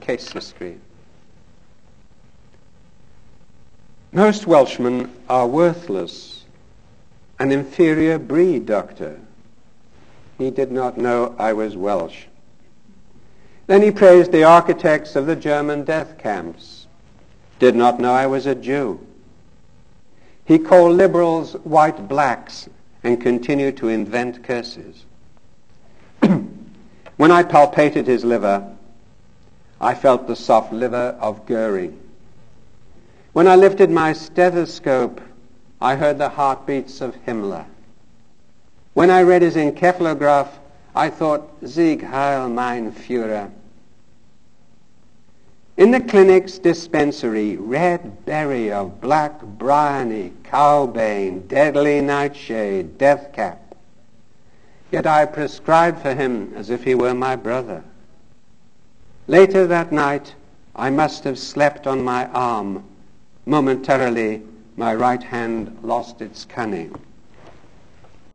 Reading by Dannie Abse